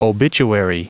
Prononciation du mot obituary en anglais (fichier audio)
Prononciation du mot : obituary